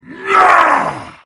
moarg_attack_01.mp3